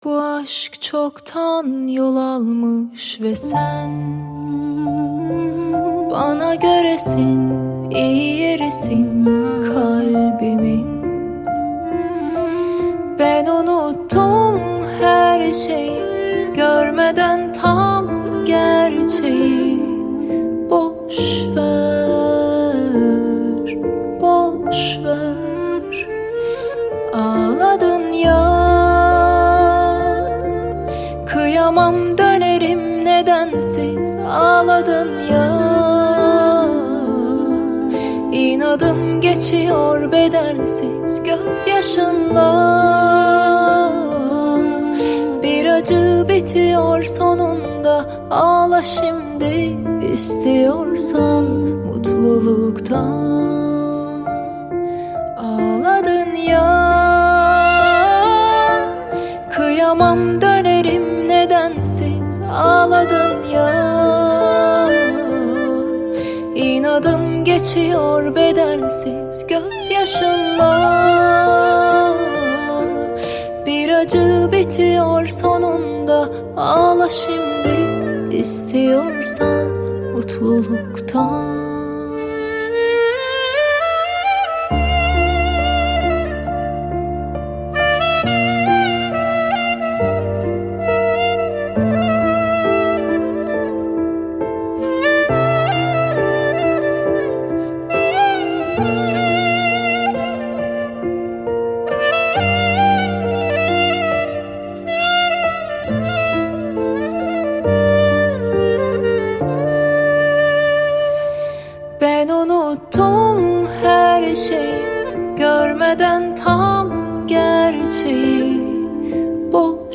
Турецкие песни